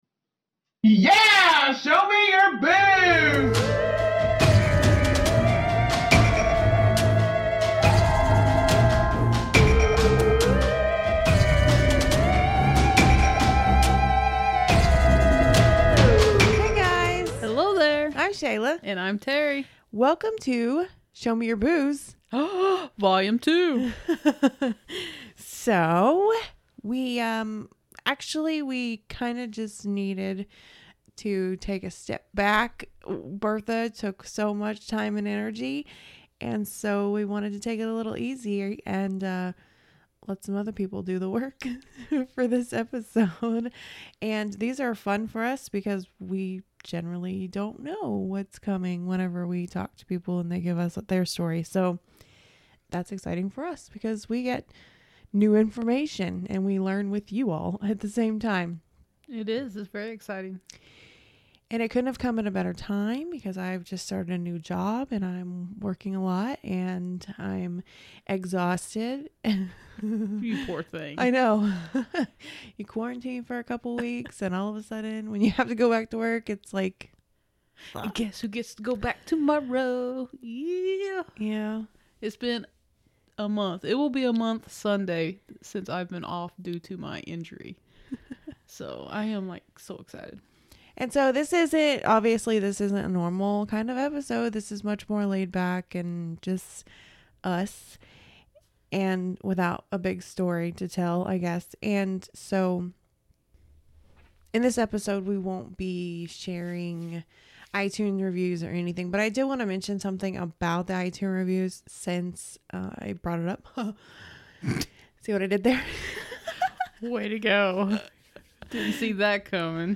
In volume two of Show Me Your Boos, we talk to a listener from right here in Missouri.